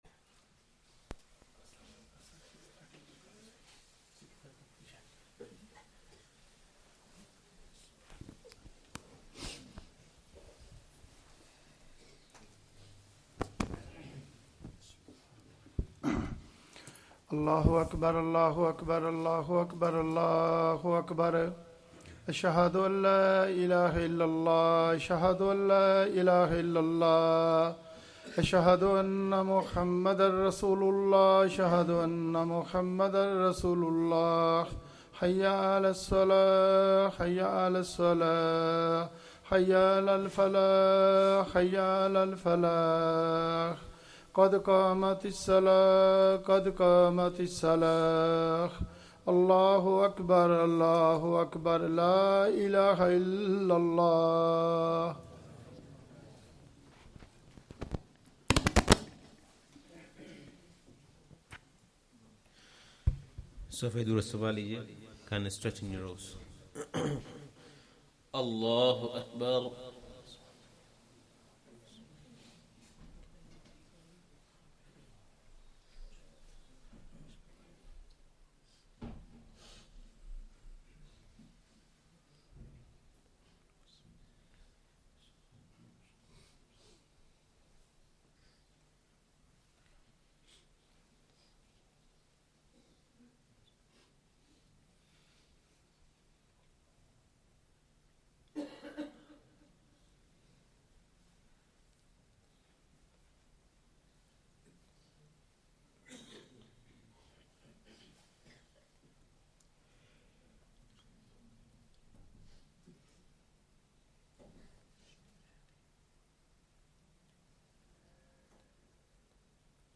Asr salah + Bayaan
Masjid Adam, Ilford